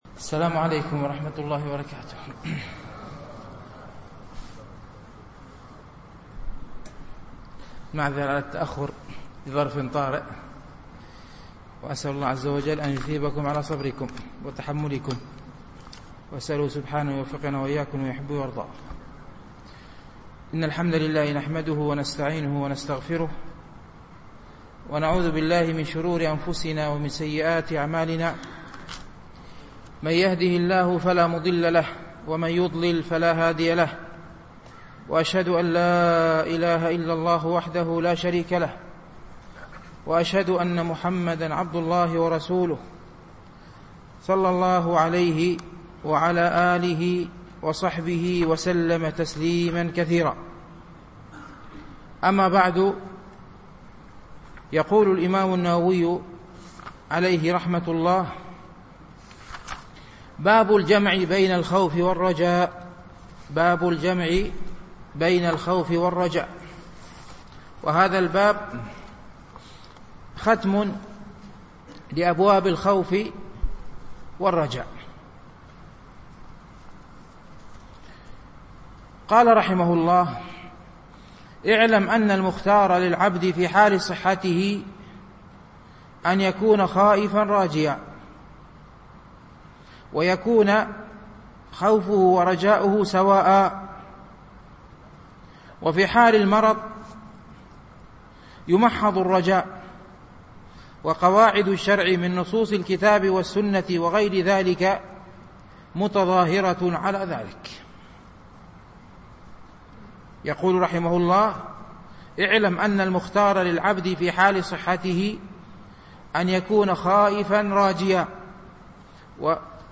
شرح رياض الصالحين - الدرس الواحد والثلاثون بعد المئة